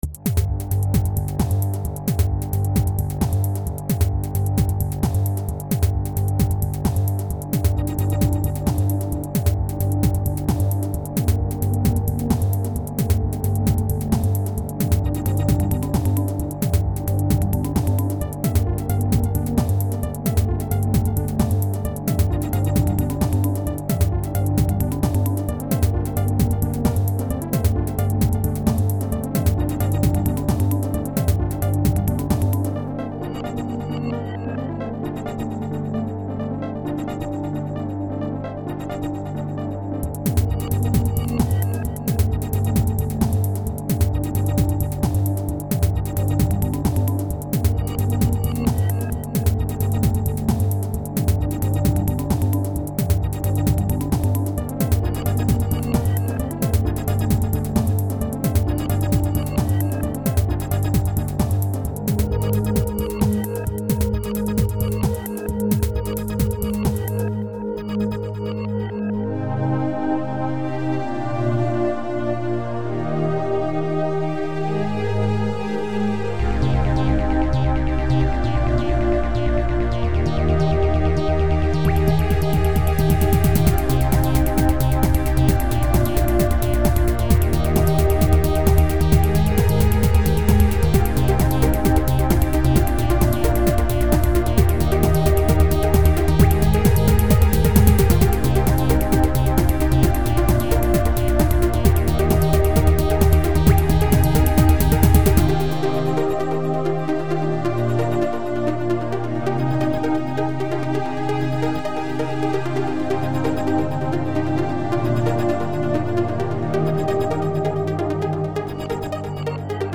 komponieren seit 1995 mit Yamaha's Control-Synthesizer CS1x, Cubase VST und FrootyLoops